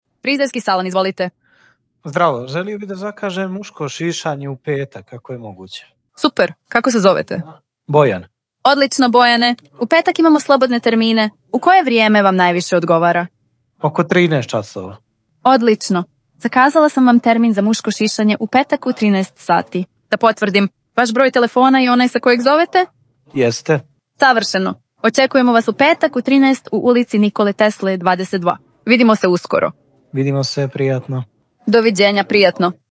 Linia je sistem zasnovan na vještačkoj inteligenciji koji automatski prima i upućuje telefonske pozive, razgovara sa korisnicima i pruža tačne informacije – prirodnim, ljudskim glasom.
Preslušajte primjere AI glasovnog agenta